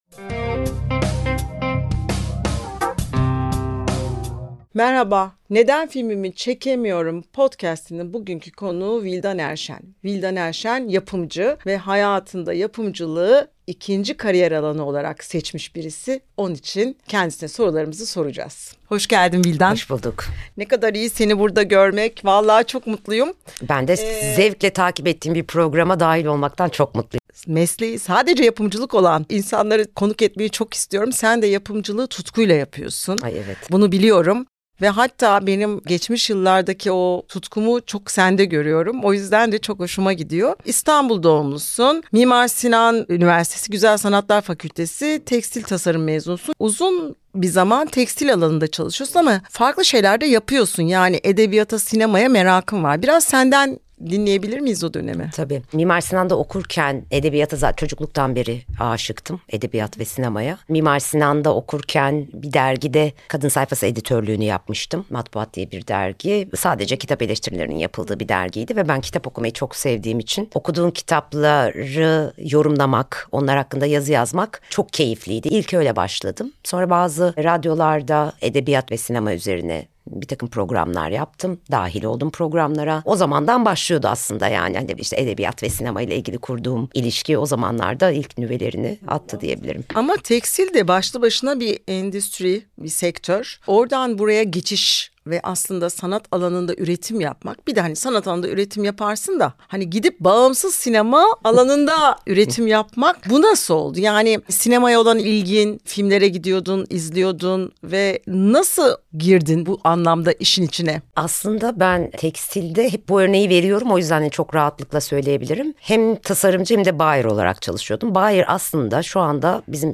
Sinema alanında senarist, yönetmen, yapımcı, oyuncu, müzisyen vb…üretim yapan sanatçıların kariyer yolculuğundaki başarılar, başarısızlıklar, inişler, çıkışlar, reddedilmeler, ödüller, sevinçler üzerine bir sohbet.
Sohbeti, Türkiye’ye son 20 yılda Altın Palmiye başta olmak üzere pek çok ödül kazanan, Türkiye’den Oscar Akademiye kabul edilen ilk yapımcı Zeynep Atakan gerçekleştiriyor.